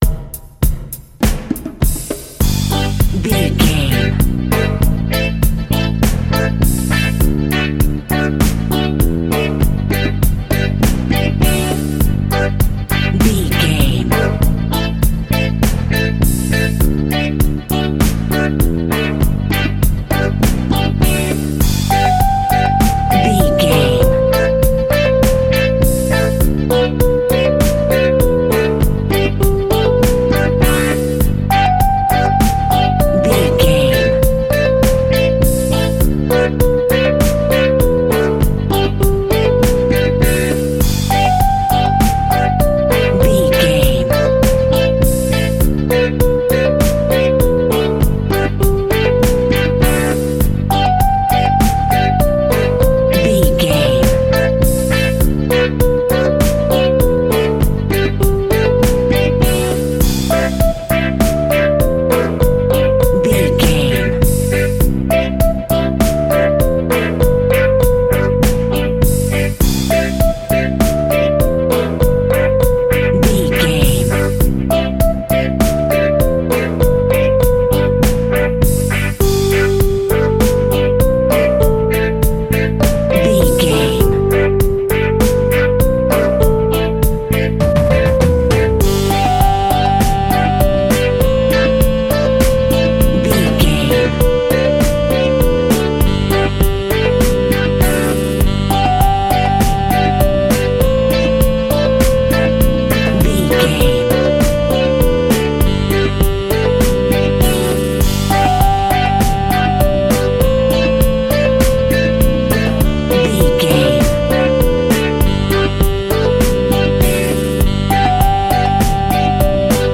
Aeolian/Minor
instrumentals
laid back
chilled
off beat
drums
skank guitar
hammond organ
transistor guitar
percussion
horns